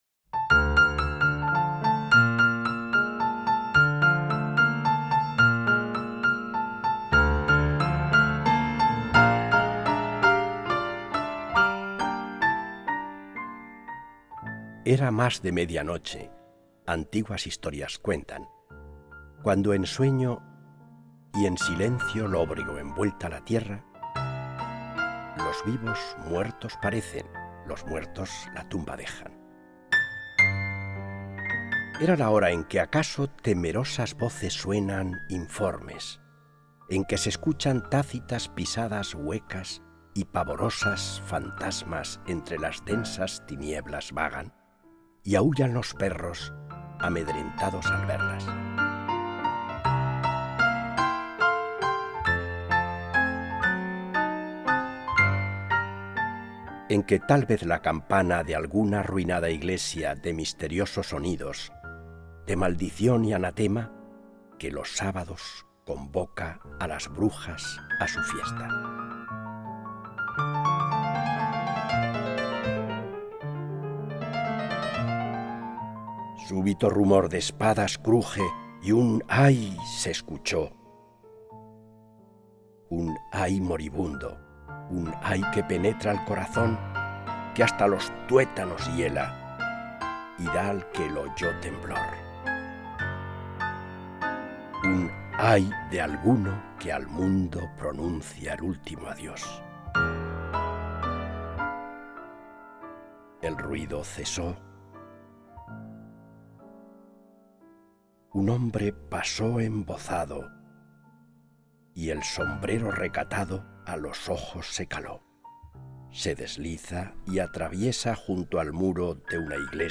recitado